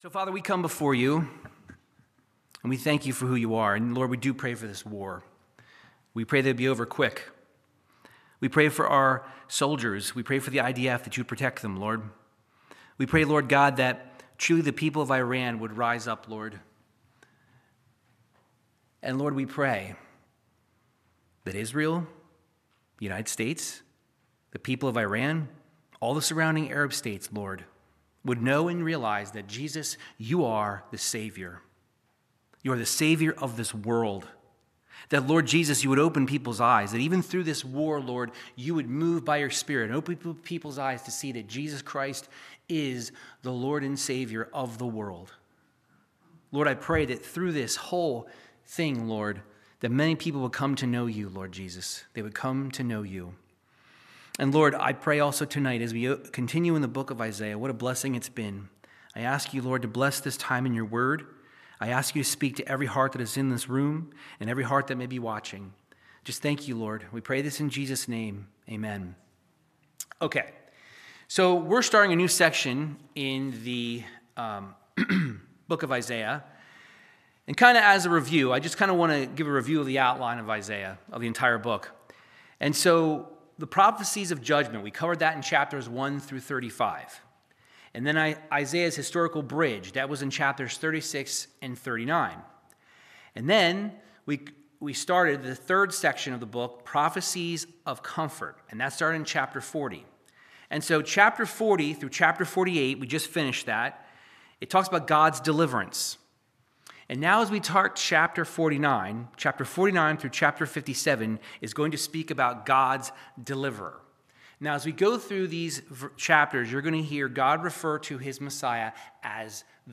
Verse by verse Bible teaching through the Book of Isaiah chapters 49 through 50 verse 5